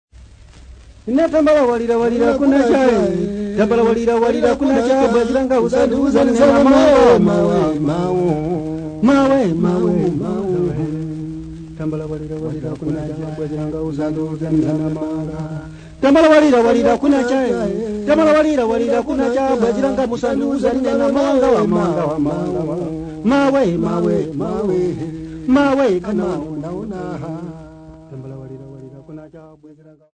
Dance music
Field recordings
Africa Malawi Blantyre f-sa
Traditional machipo dance song unaccompanied